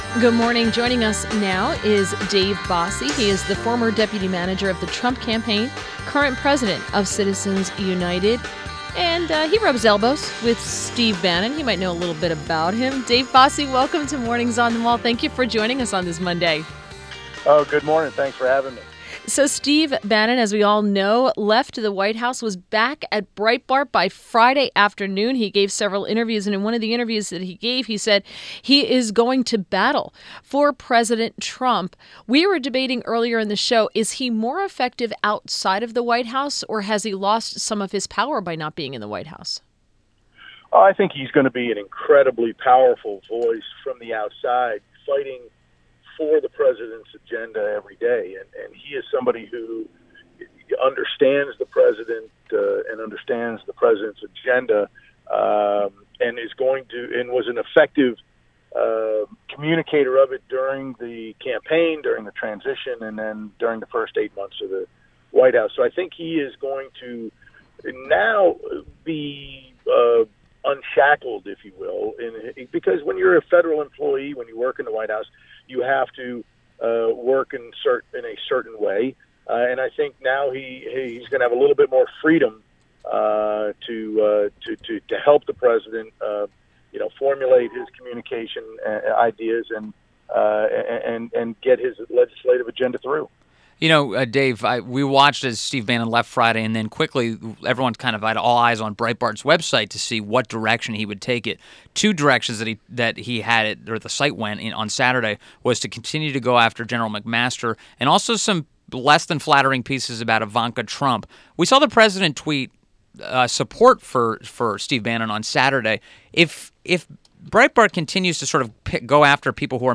WMAL Interview - DAVID BOSSIE 08.21.17
INTERVIEW - DAVID BOSSIE - former Deputy Manager of the Trump campaign and current president of Citizens United, friend of Steve Bannon